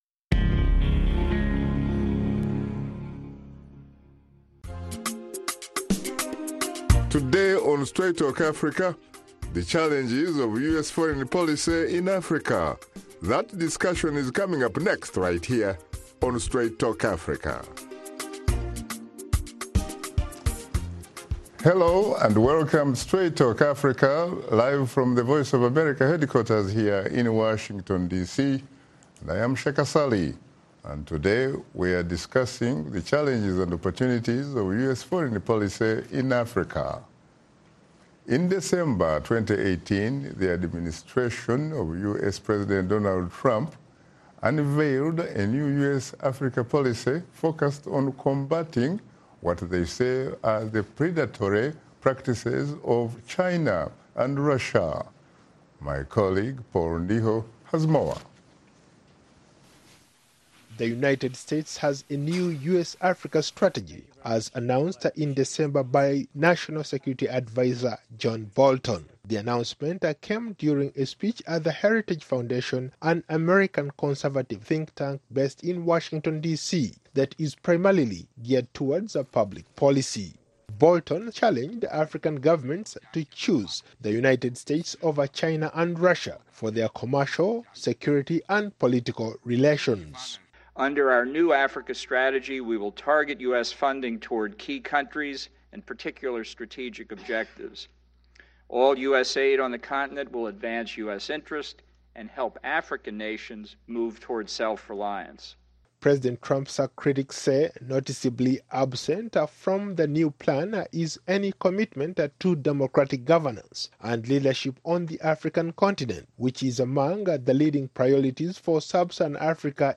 In this episode of Straight Talk Africa, host Shaka Ssali explores the engagement of the U.S. in Africa. He is joined by Ruth A. Davis, Former U.S. Ambassador to Benin, Charles A. Ray, Former U.S. Ambassador to Zimbabwe and Alonzo Fulgham, Former Acting Administrator of the United States Agency for International Development (USAID).